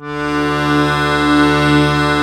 D1 BUTTON -L.wav